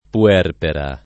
puerpera [ pu- $ rpera ] s. f.